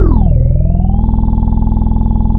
FOXXY BASS-R.wav